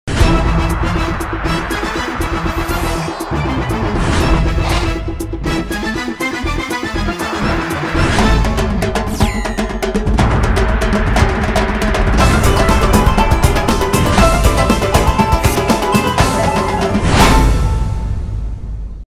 دانلود آهنگ اخبار از افکت صوتی اشیاء
جلوه های صوتی